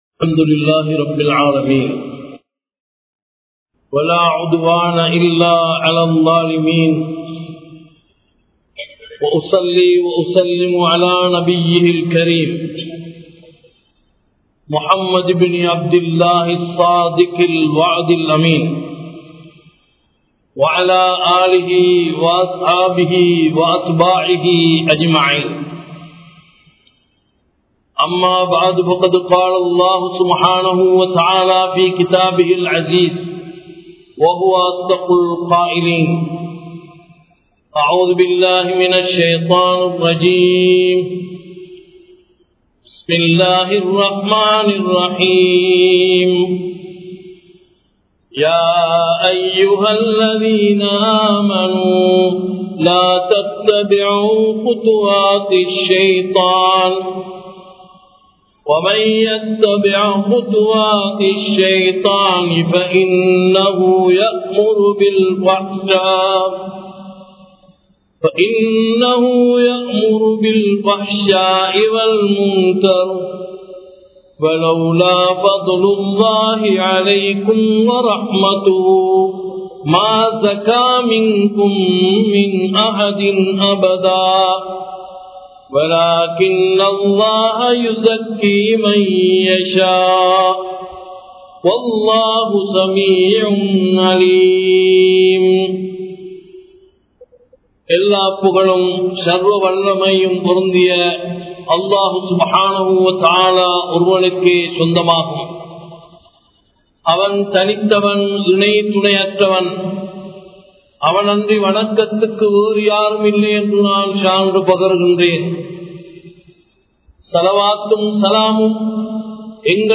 Boathaivasthu Paavanaium Em Samoohamum (போதைவஸ்து பாவனையும் எம் சமூகமும்) | Audio Bayans | All Ceylon Muslim Youth Community | Addalaichenai
Colombo 03, Kollupitty Jumua Masjith 2017-11-03 Tamil Download